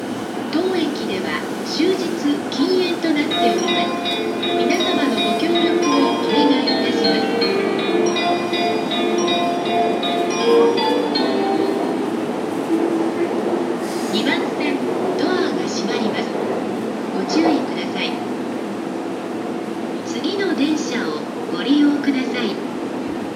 大井町駅　Oimachi Station ◆スピーカー：JVC横長型
発車メロディは2ターンで1コーラスです。
2番線発車メロディー